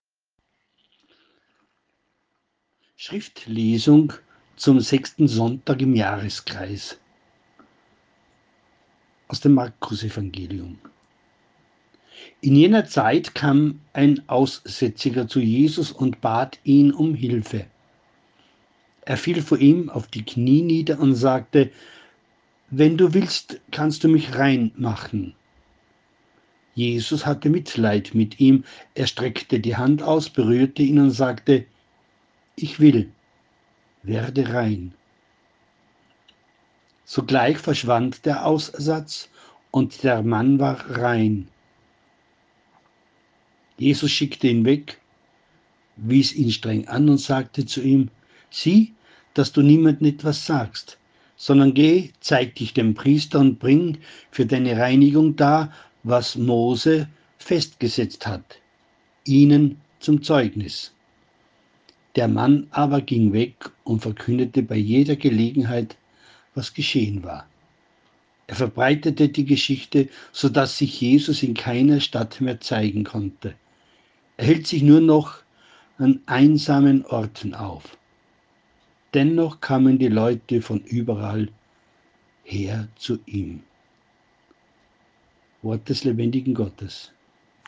Audio-Evangelium, Mk 1,40-45